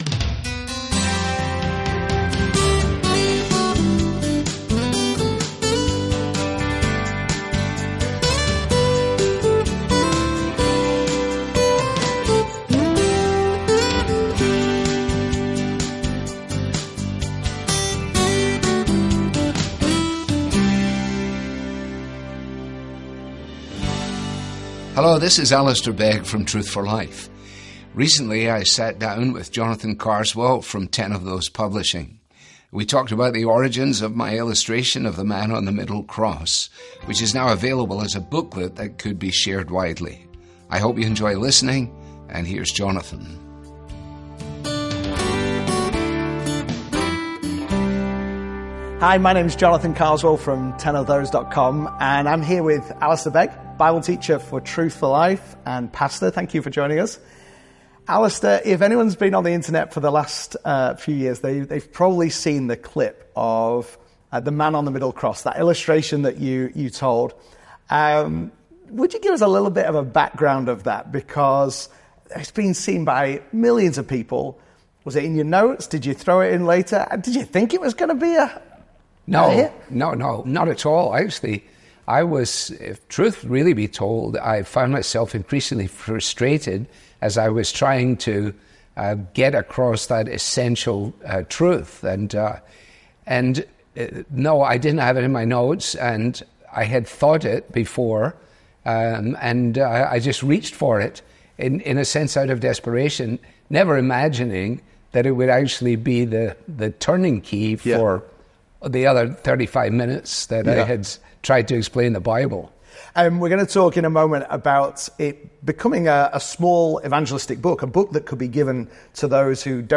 The Man on the Middle Cross (Interview)